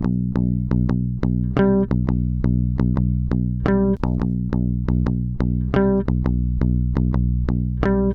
Na drive straně s vypnutým kompresorem, distortionem a IR je to výrazně rovnější, jen je tu drobný HPF (nebo taky lehká  komprese)?